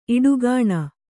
♪ iḍugāṇa